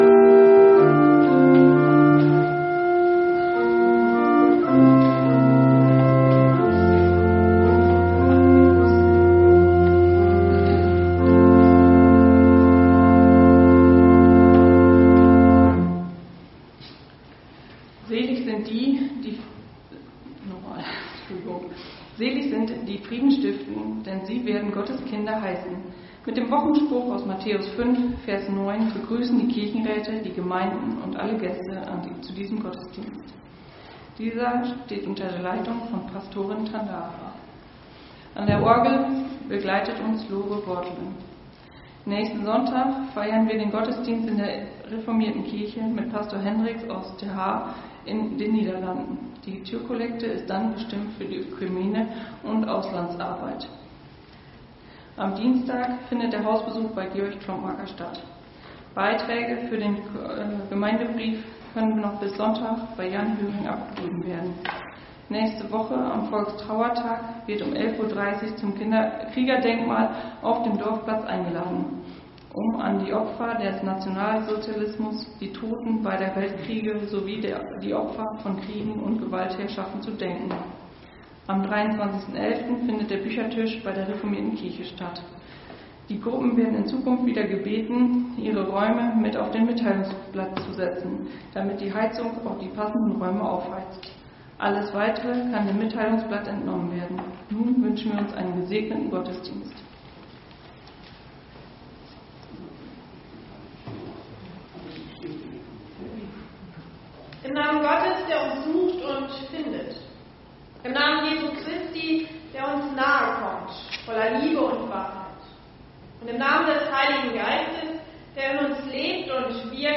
Gottesdienst Sonntag 09.11.2025 | Evangelisch-altreformierte Kirchengemeinde Laar
Wir laden ein, folgende Lieder aus dem Evangelischen Gesangbuch mitzusingen: Lied 321, 3 , Lied 182, 1 – 6, Lied 600, 1 – 4, Lied 395, 1 – 3, Lied So ist Versöhnung, 1 – 3, Lied 667, 1 – 3, 5